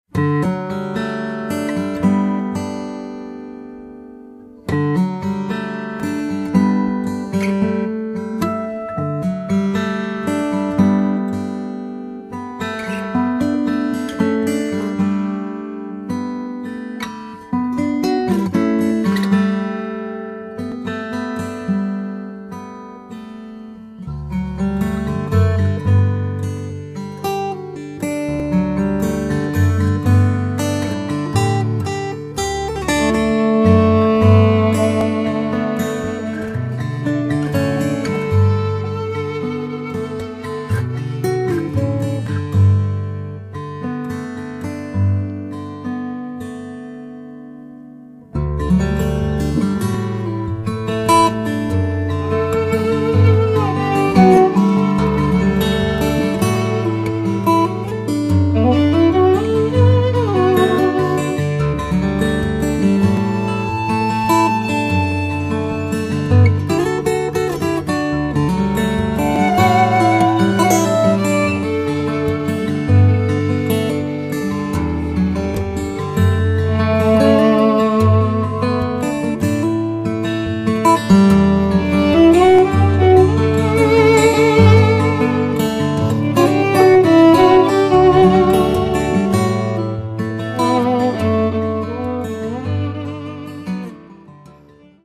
chitarra, armonica
viola e piano